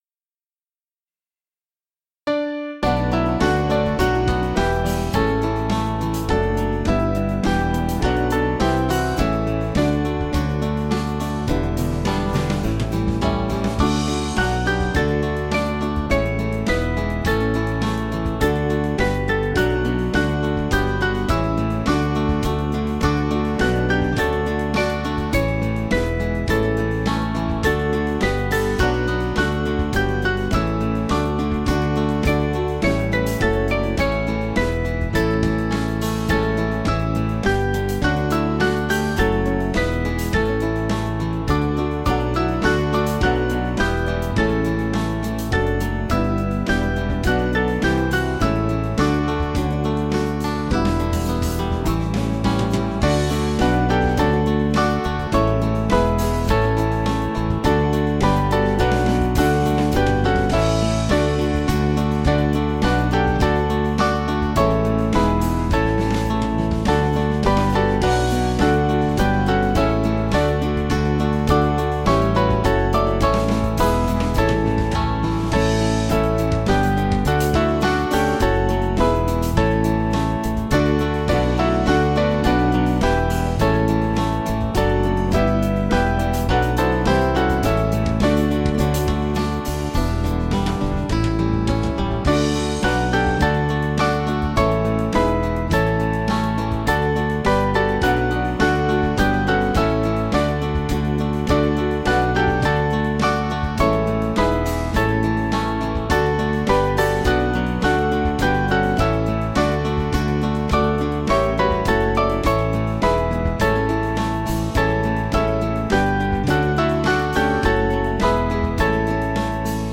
BIAB